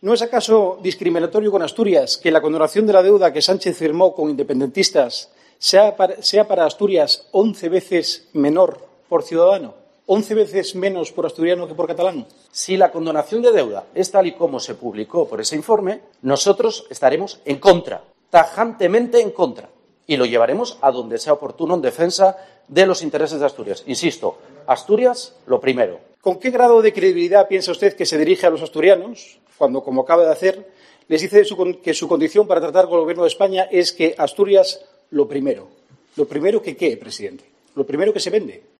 Queipo y Barbón debaten en la Junta sobre la quita de la deuda